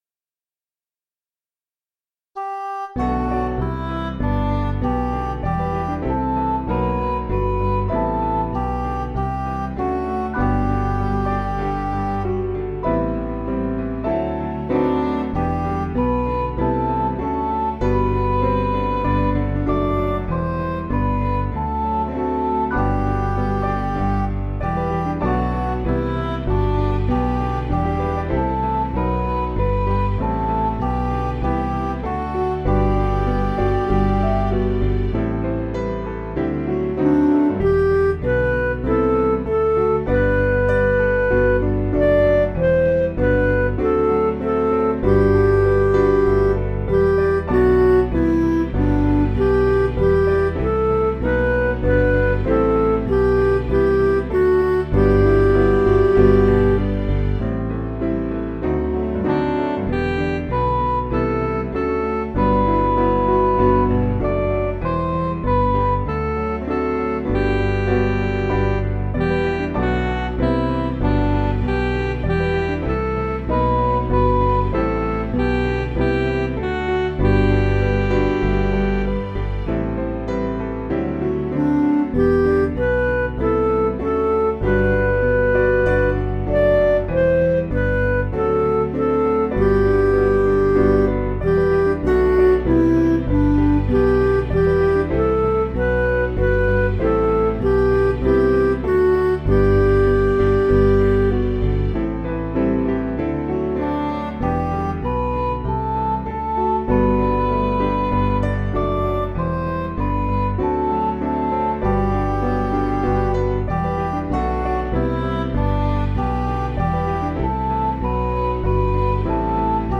Piano & Instrumental
(CM)   5/G